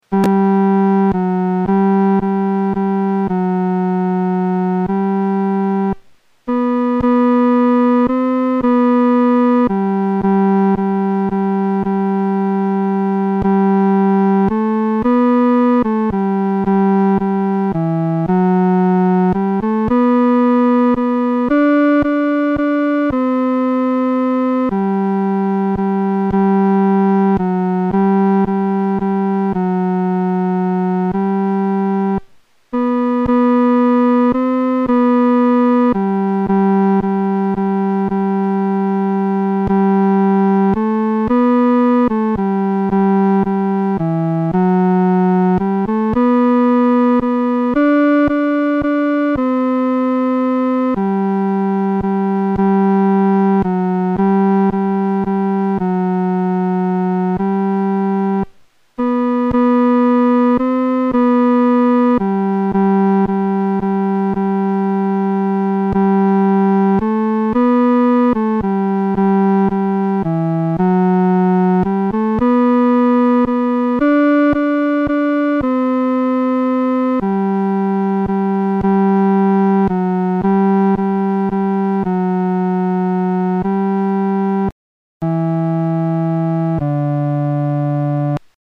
伴奏
男高
指挥在带领诗班时，表情和速度应采用温柔而缓慢地。